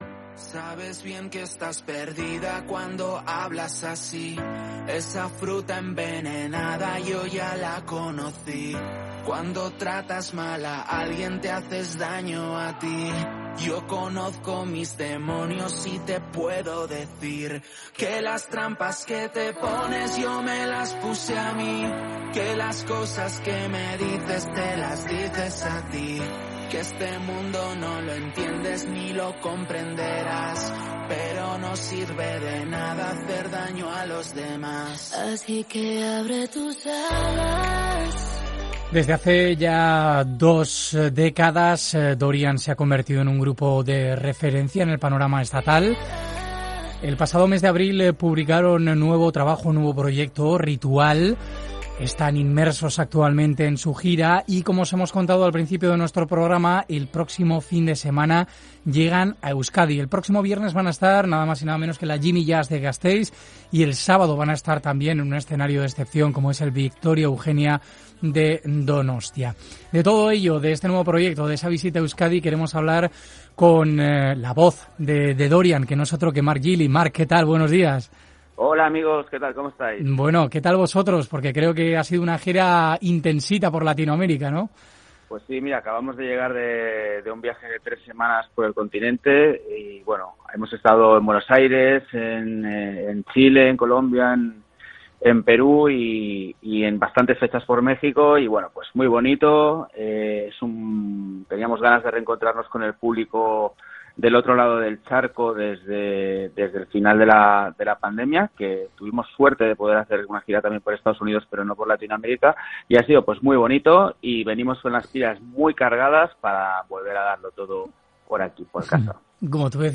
Hoy hablamos con Marc Gili, vocalista y letrista de Dorian, de su música y de sus letras de espíritu reivindicativo y reflexivo con la sociedad que vivimos. Una charla sobre música y la vida.